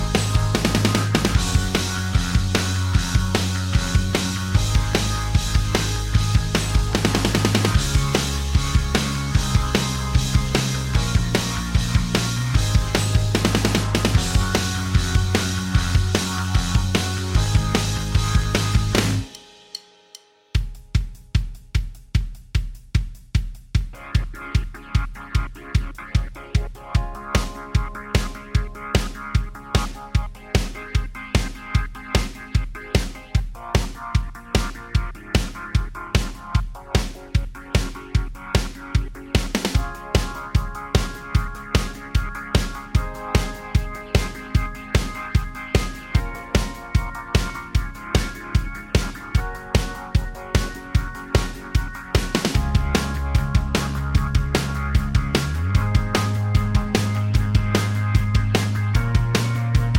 Minus Main Guitars For Guitarists 3:46 Buy £1.50